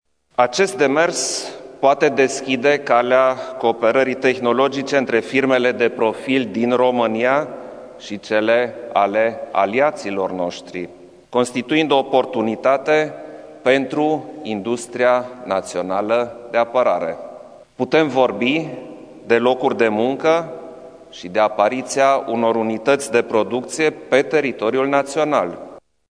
Aşa a afirmat, în urmă cu puţin timp, presedintele Klaus Iohannis, după consultările avute la Palatul Cotroceni cu reprezentanţii partidelor politice parlamentare.
Demersul poate deschide calea cooperării între firmele româneşti şi cele ale aliaţilor, a menţionat preşedintele Iohannis: